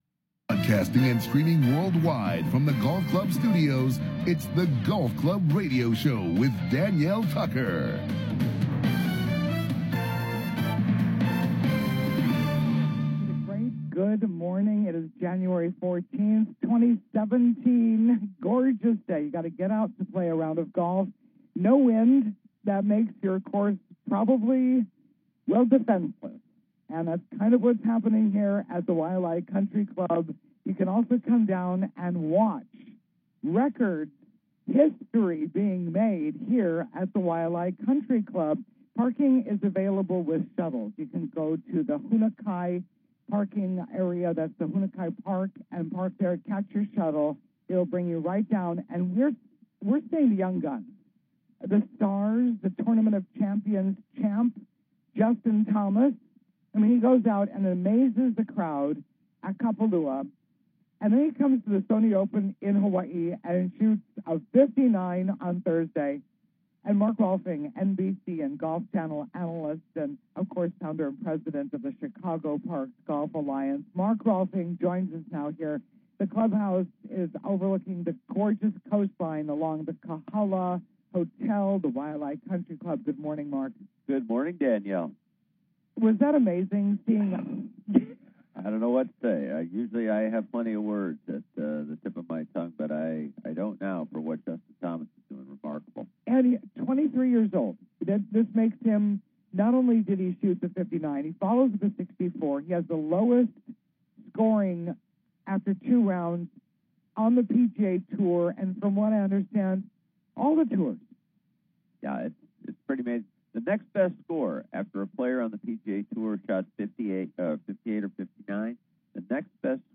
Live From The Sonny Open
Mark Rolfing: NBC and Golf Channel Analyst